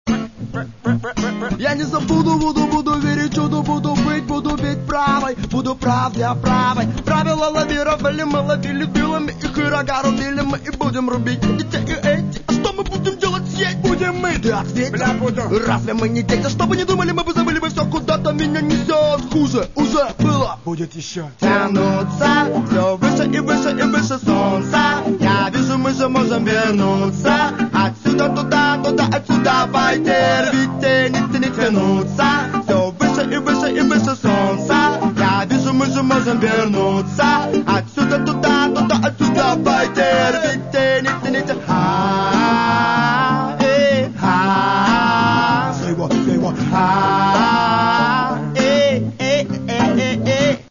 Каталог -> Рок и альтернатива -> Регги